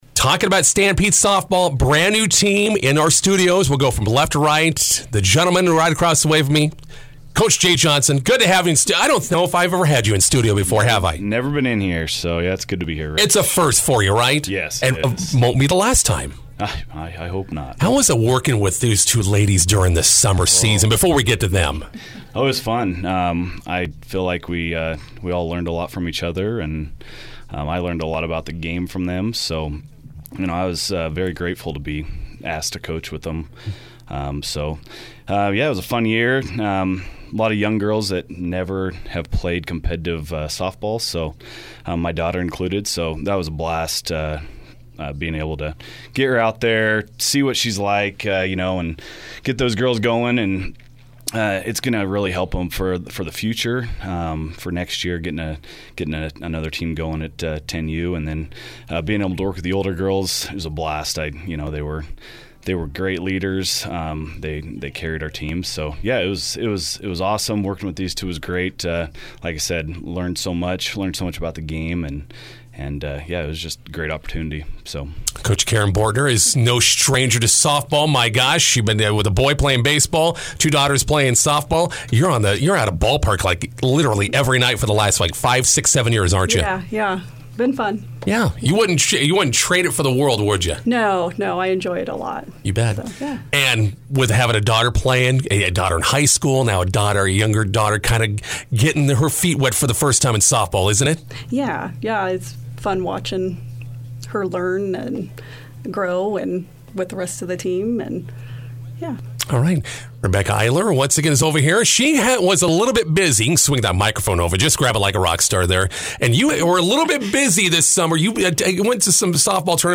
INTERVIEW: Stampede 10-an-under softball girls finish up first year on the field.